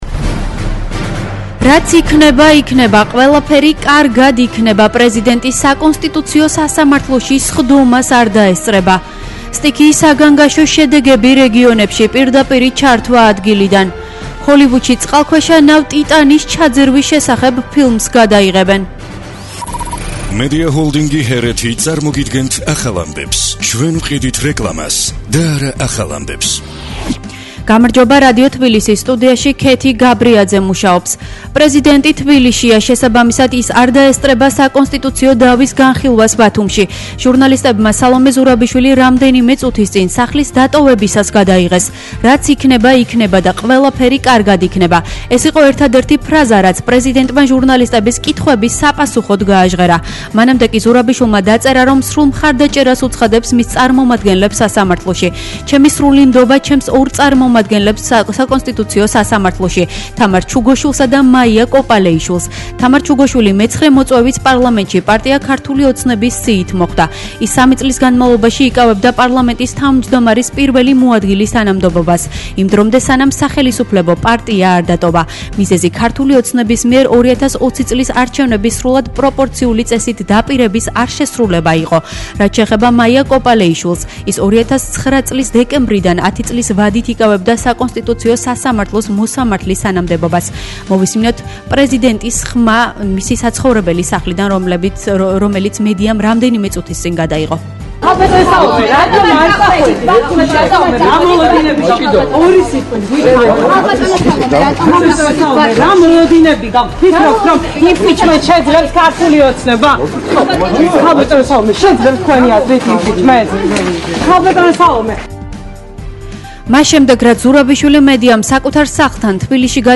ახალი ამბები 12:00 – 13:00 საათზე